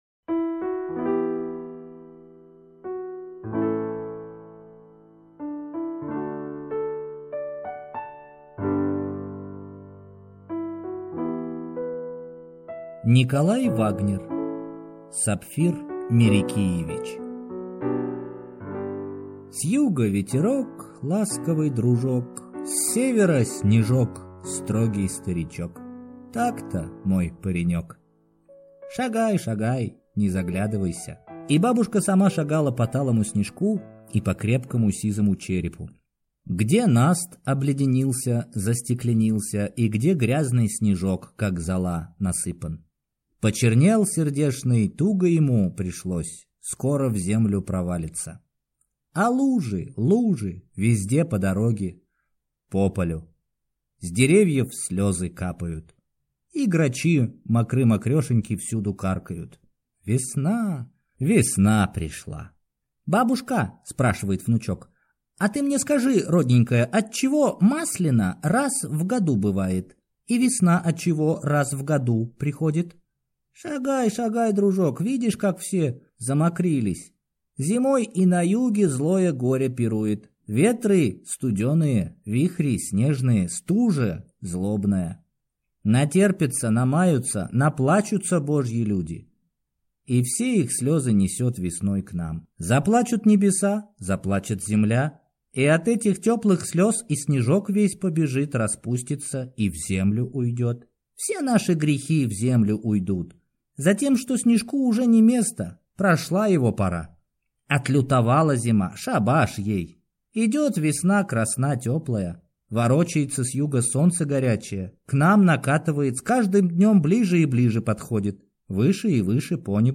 Аудиокнига Сапфир Мирикиевич | Библиотека аудиокниг
Прослушать и бесплатно скачать фрагмент аудиокниги